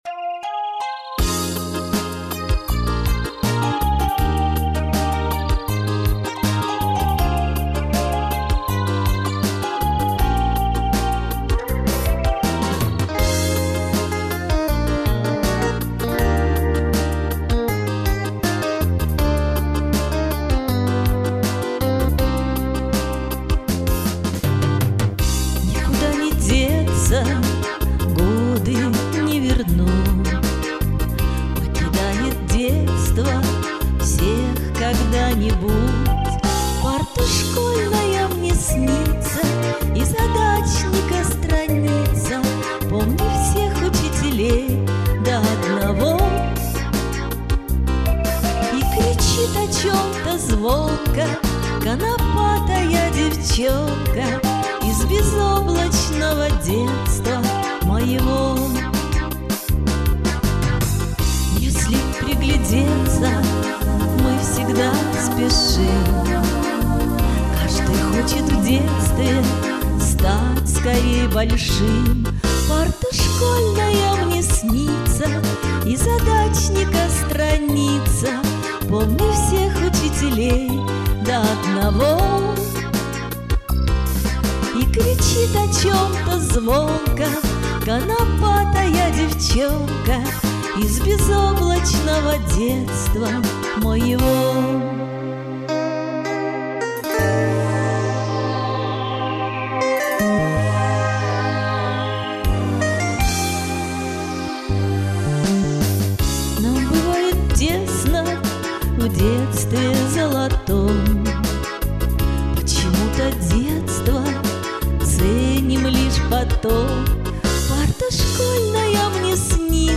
ах, как прям по душе разливаетесь!!!bravo_big